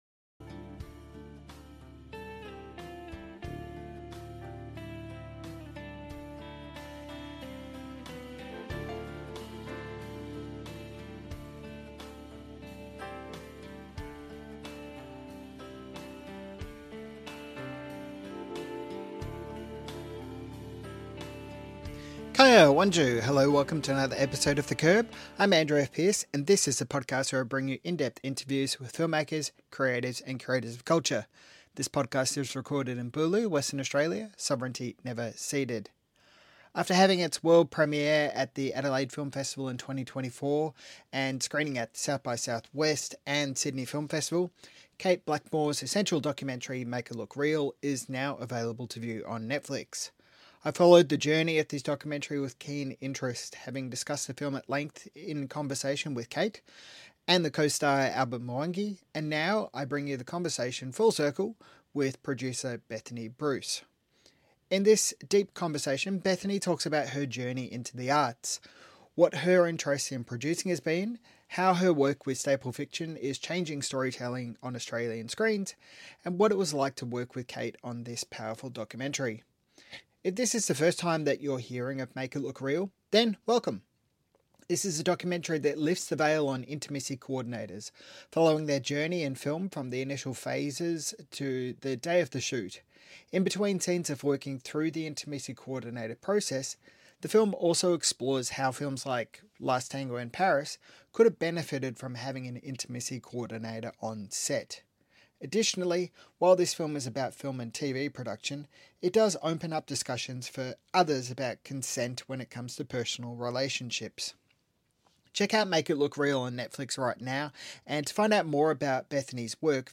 In this deep conversation